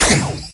laser_04.ogg